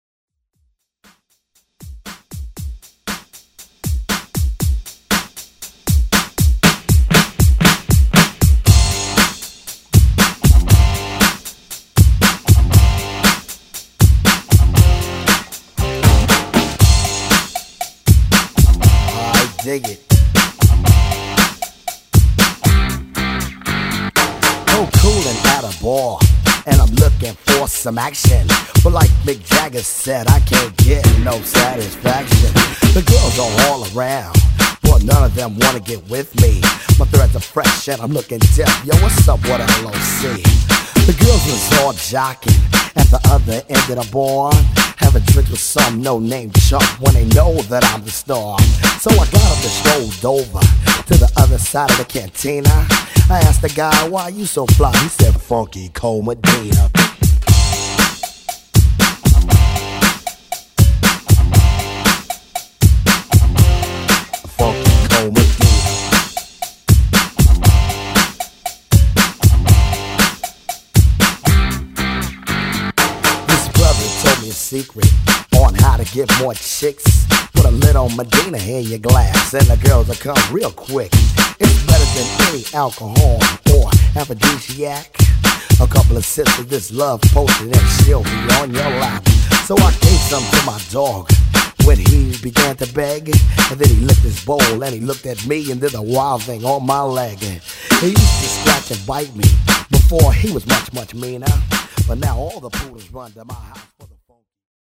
Genre: BOOTLEG Version: Clean BPM: 105 Time